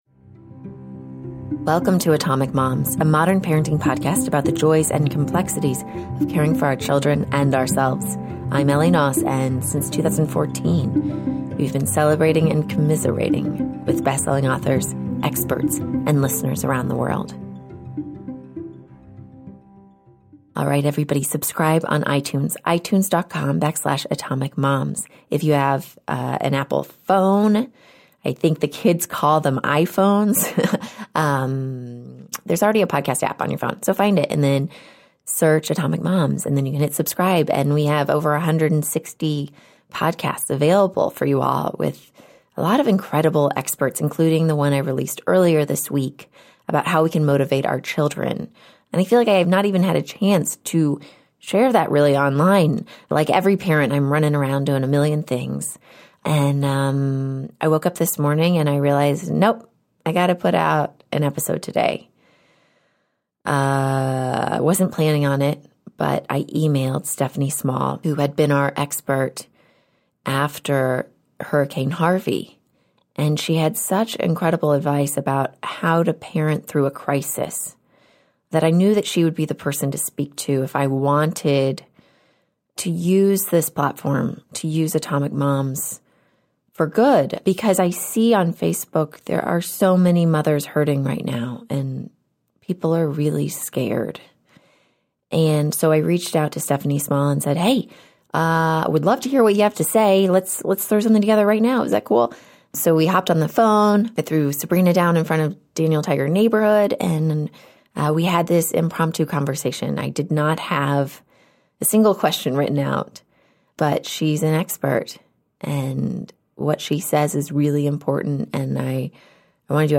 I'm honored that she took my call.